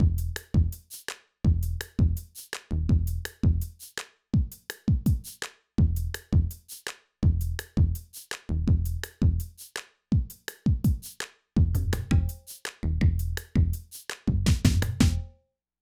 Boss Dr Rhythm DR-3 Sample Pack_Loop5.wav